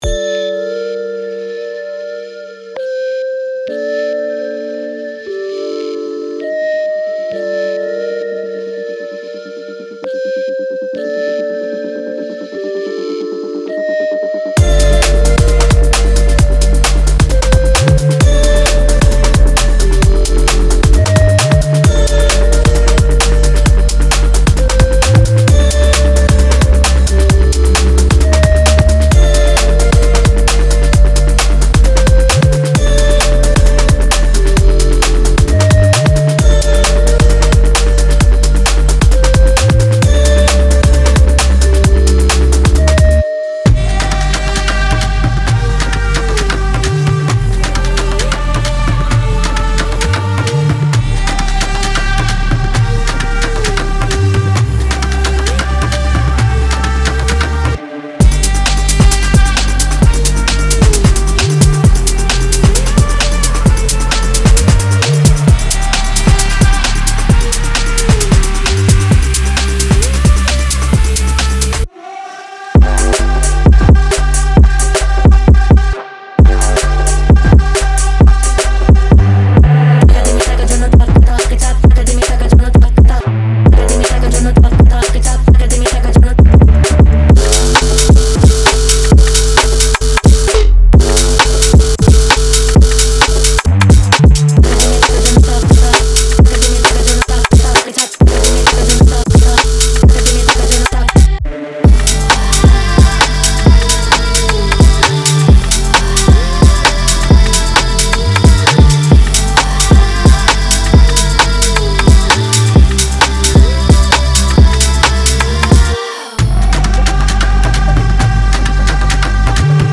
Puerto Rican producer